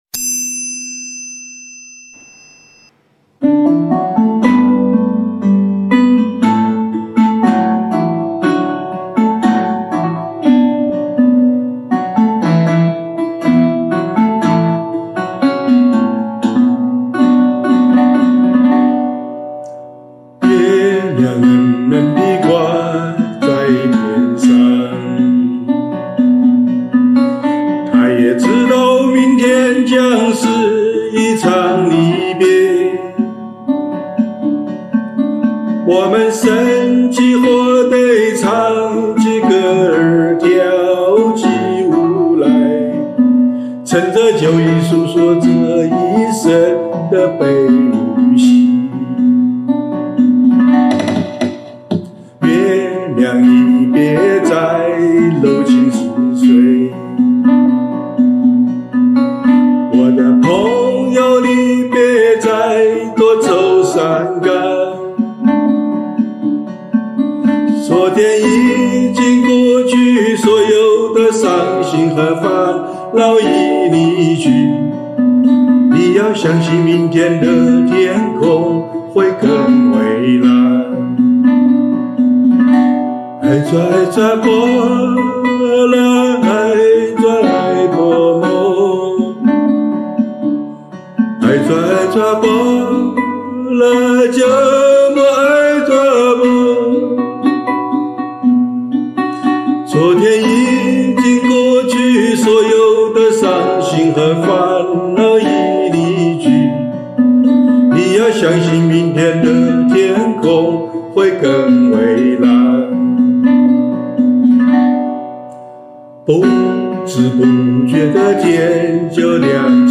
曲谱类型：指弹谱